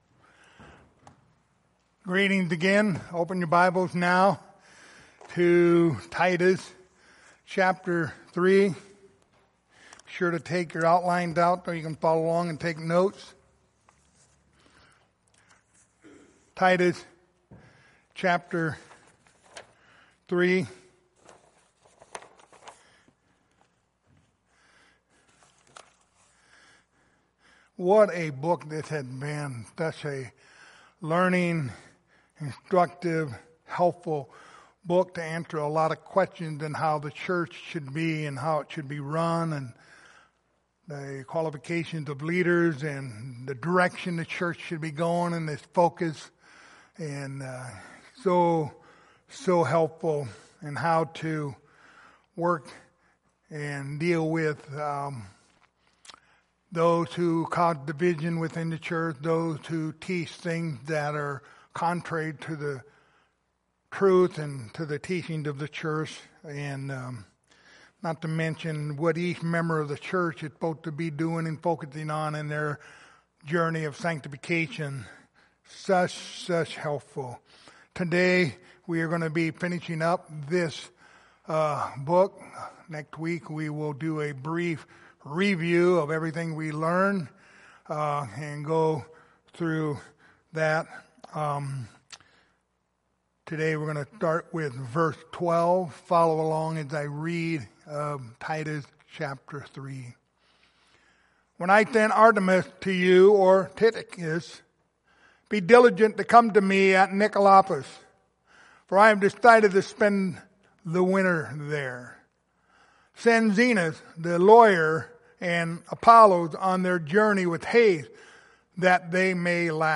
Pastoral Epistles Passage: Titus 3:12-15 Service Type: Sunday Morning Topics